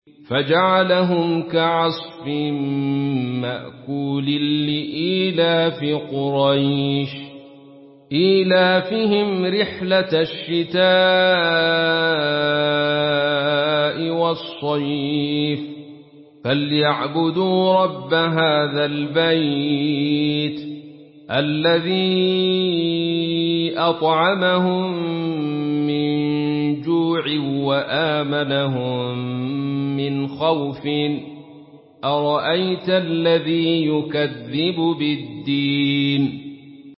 Surah Quraish MP3 by Abdul Rashid Sufi in Khalaf An Hamza narration.
Murattal